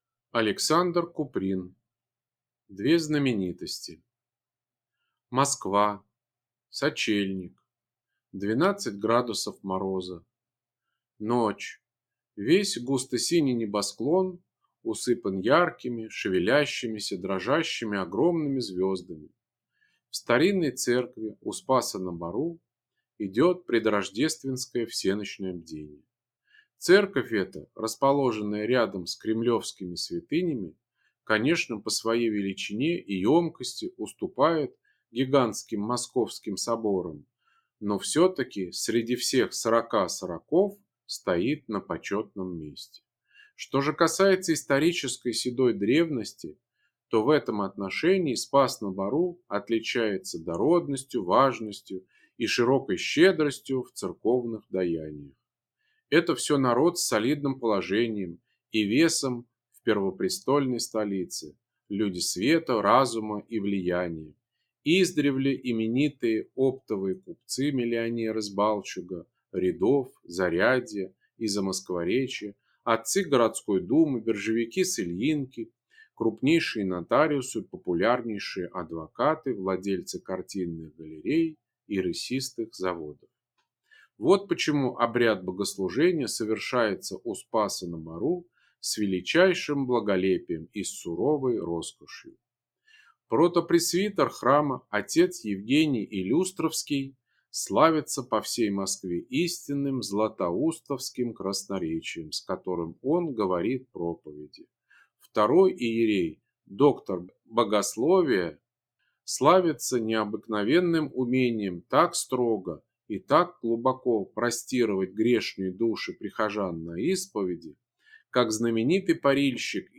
Аудиокнига Две знаменитости | Библиотека аудиокниг